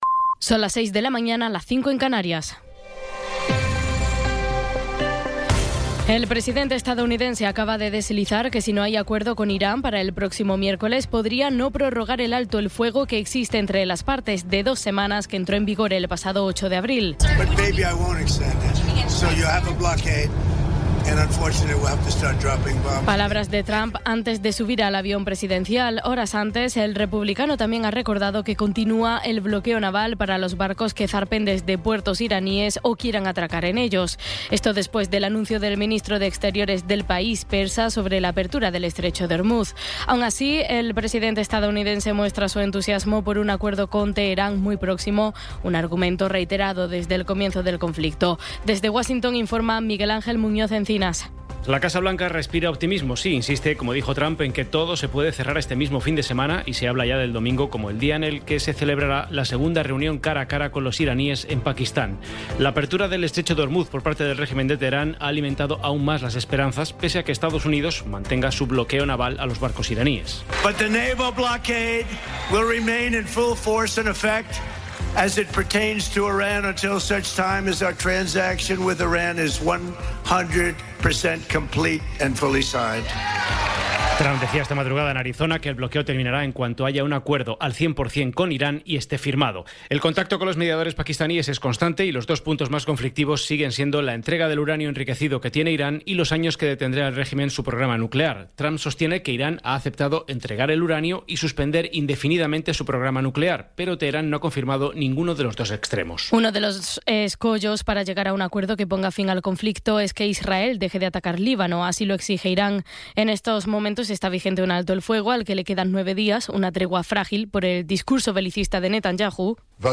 Resumen informativo con las noticias más destacadas del 18 de abril de 2026 a las seis de la mañana.